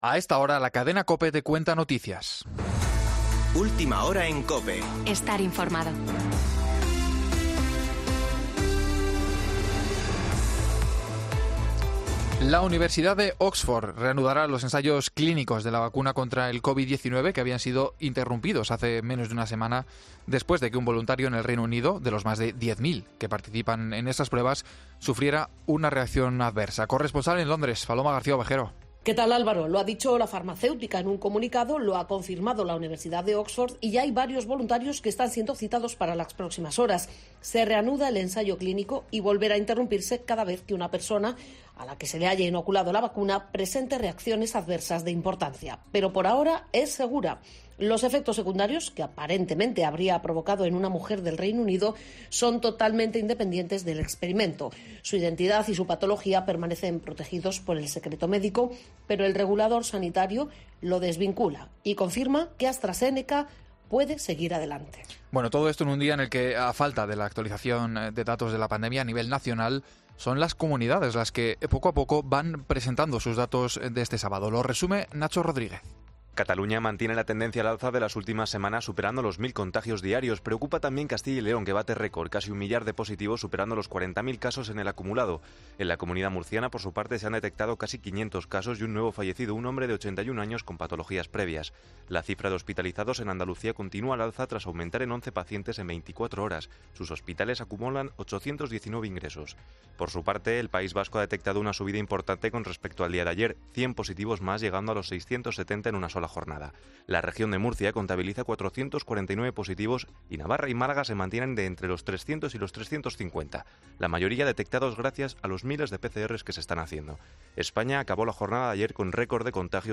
AUDIO: Boletín de noticias de COPE del 12 de septiembre de 2020 a las 19.00 horas